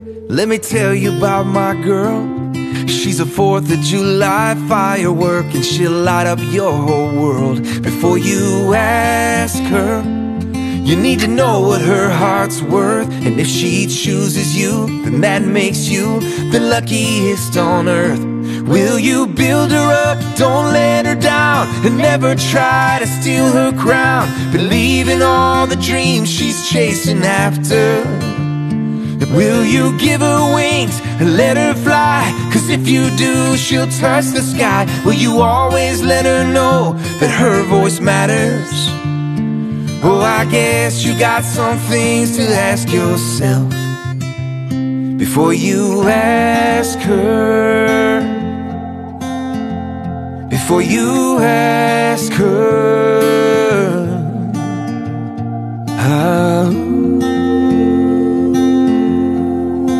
live in New York!!!